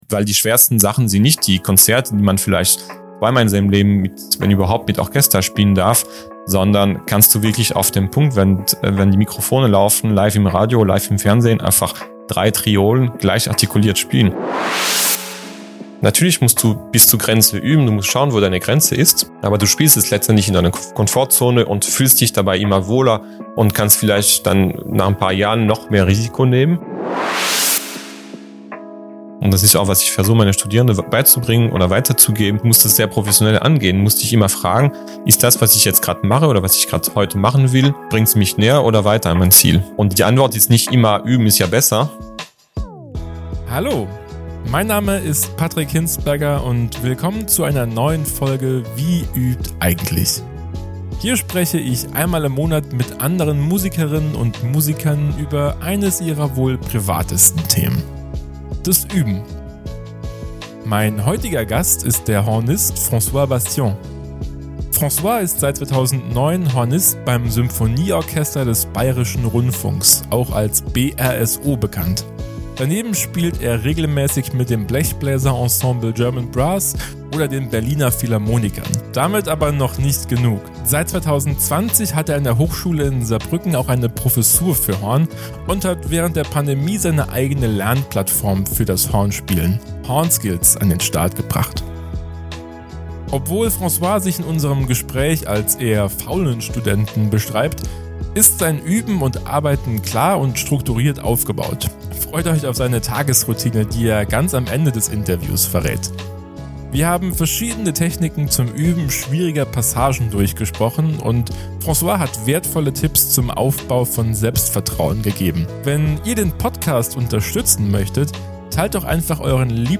Freut euch auf seine Tagesroutine, die er ganz am Ende des Interviews verrät.